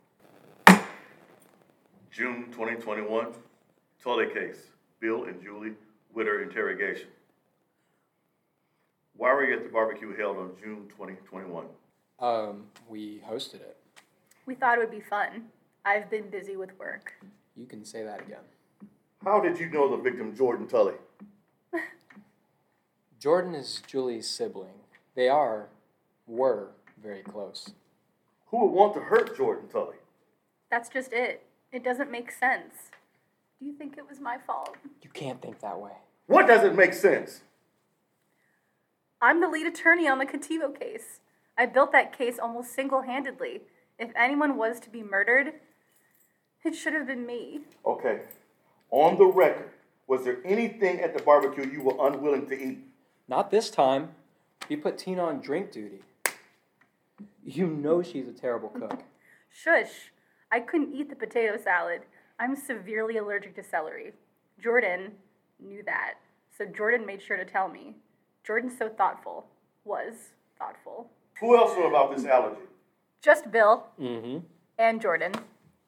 Investigate a murder using evidence files and interrogation tapes.
witness-bill-and-julie-whitter-interrogation